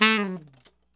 TNR SHFL A3.wav